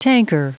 Prononciation du mot tanker en anglais (fichier audio)
Prononciation du mot : tanker